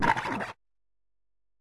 Cri de Chochodile dans Pokémon Écarlate et Violet.